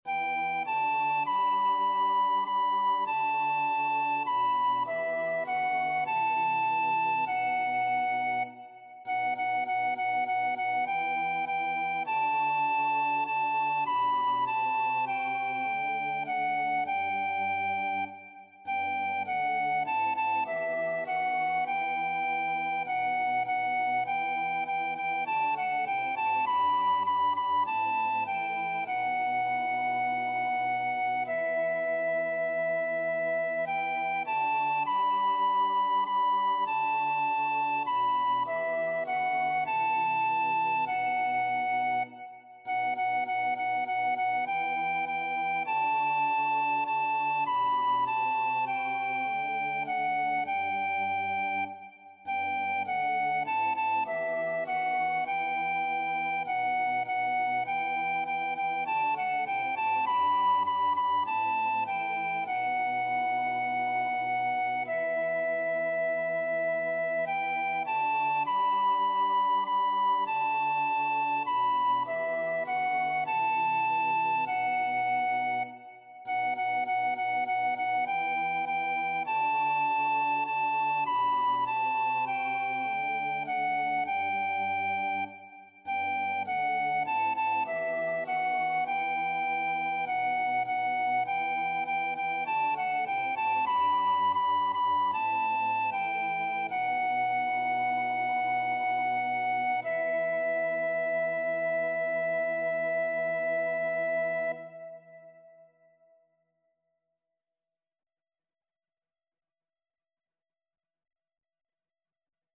1ª Voz